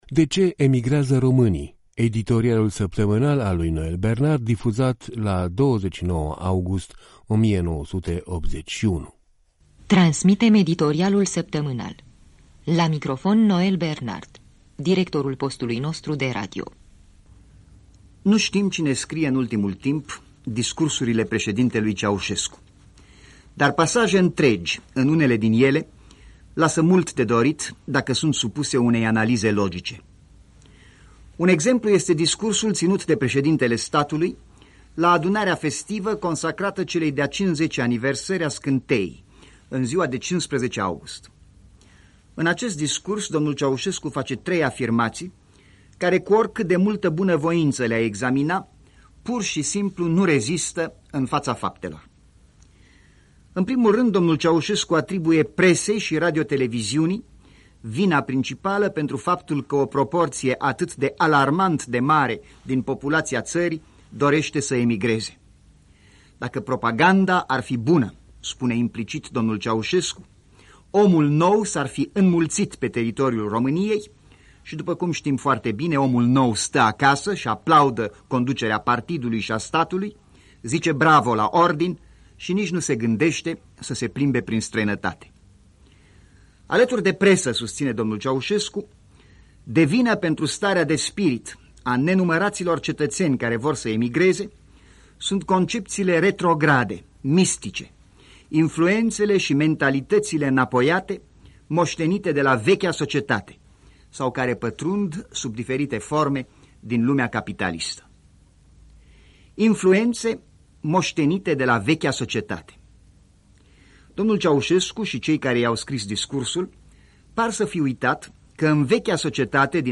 Noël Bernard: „De ce emigrează românii?” (Editorial)